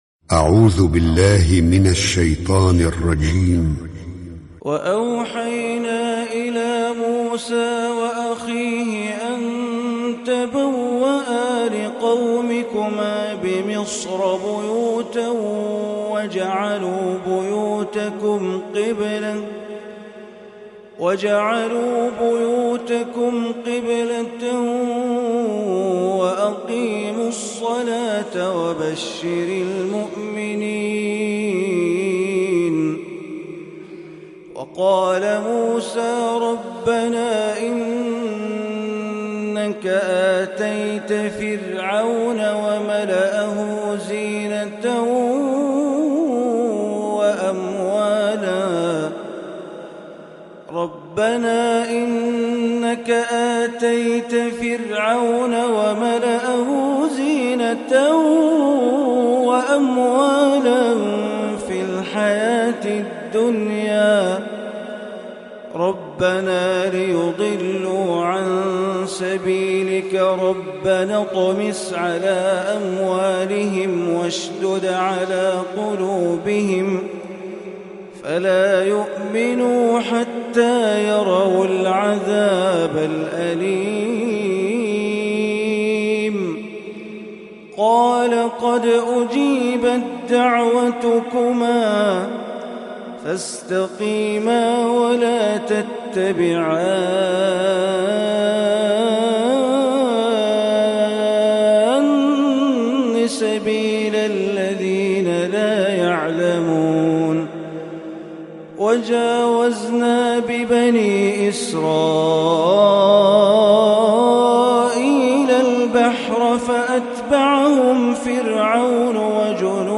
صلاة الفجر للشيخ بندر بليلة من جامع الوابل في الدمام تلاوة رائعة من سورة يونس 7-5-1437هـ > تلاوات الشيخ بندر بليلة في المنطقة الشرقية عام 1437هـ > المزيد - تلاوات بندر بليلة